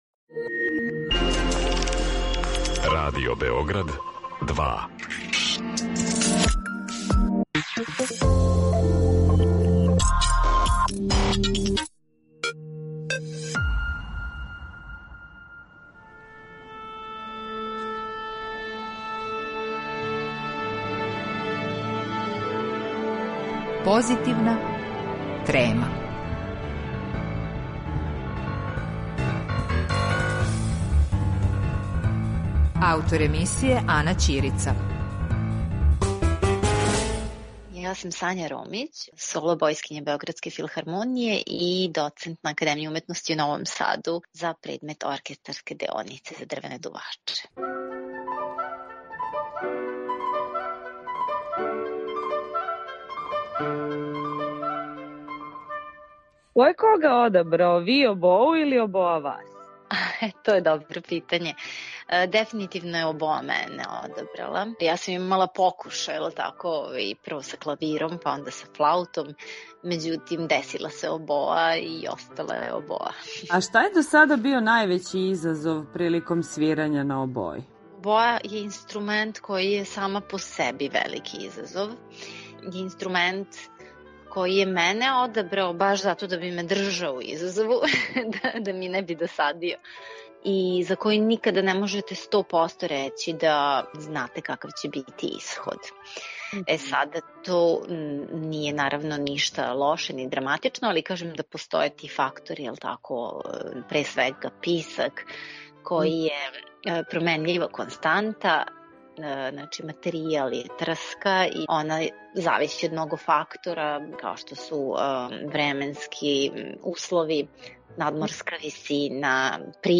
Обоисткиња